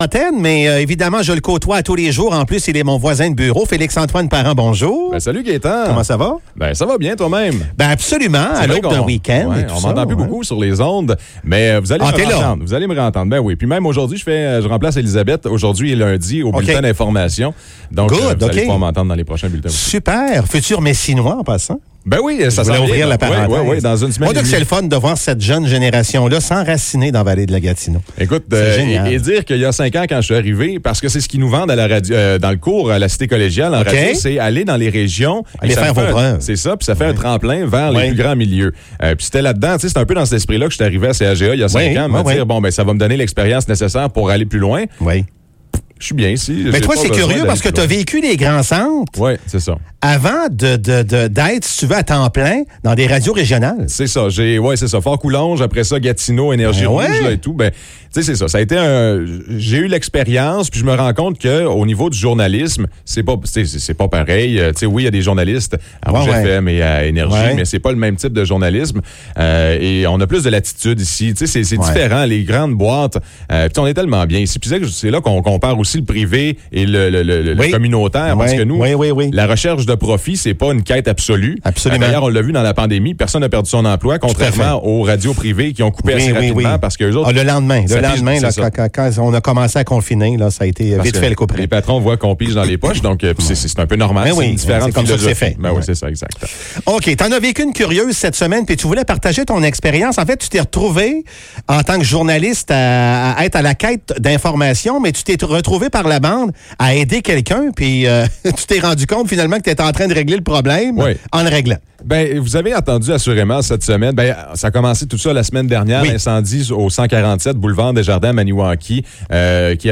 Entrevues